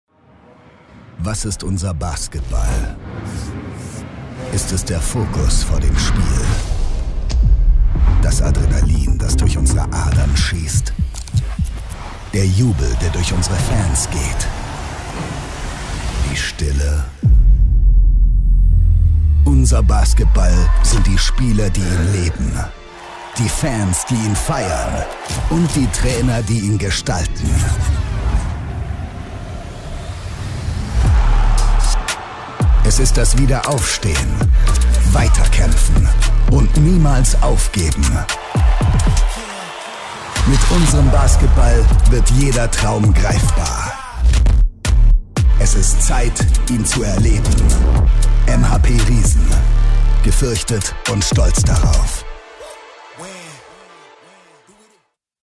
Dank seiner angenehmen sonoren Tonlage kommt er bei den Kunden immer hervorragend an.